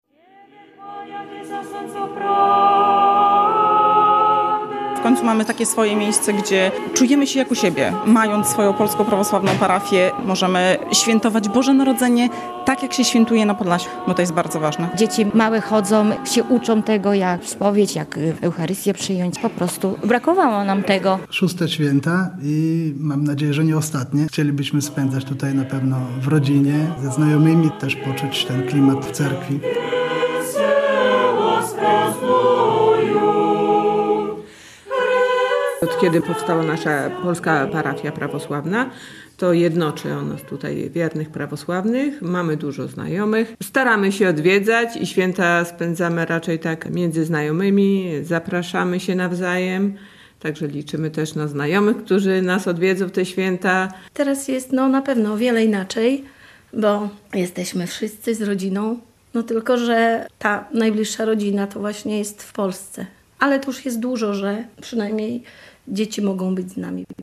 Prawosławni w Brukseli obchodzą święta Bożego Narodzenia - relacja